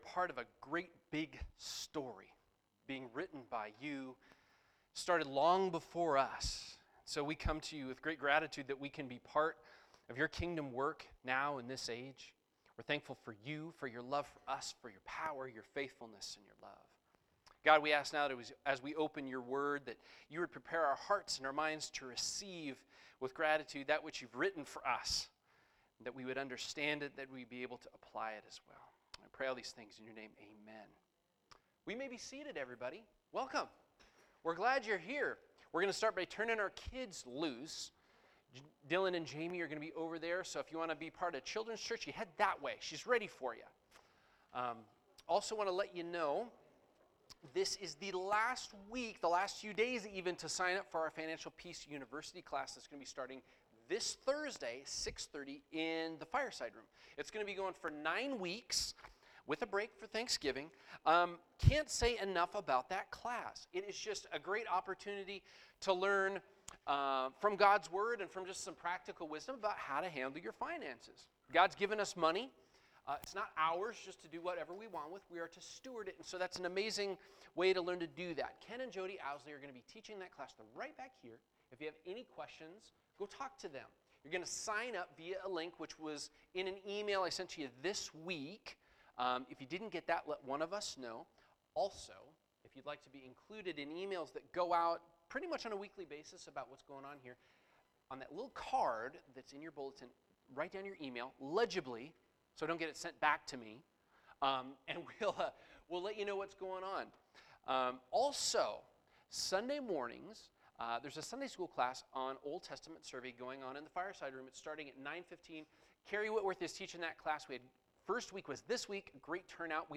Sermons Archive - Page 8 of 18 - New Life Fellowship